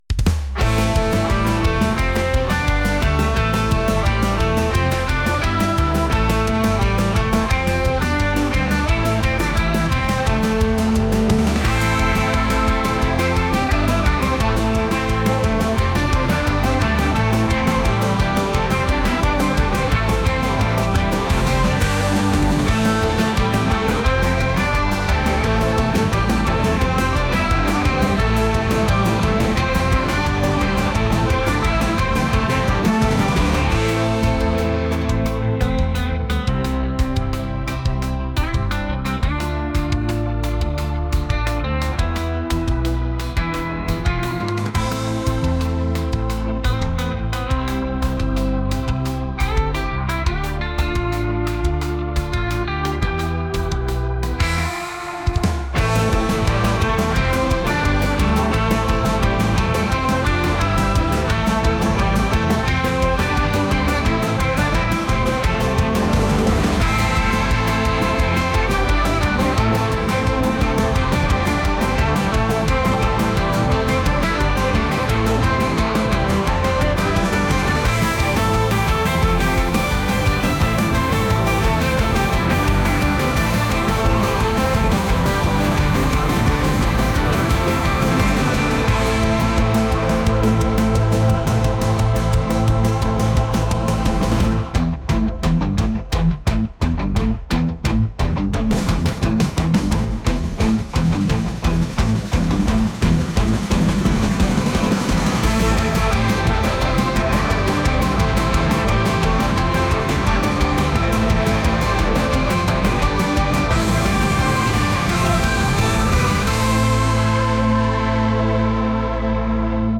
rock | retro | indie